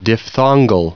Prononciation audio / Fichier audio de DIPHTHONGAL en anglais
Prononciation du mot : diphthongal
diphthongal.wav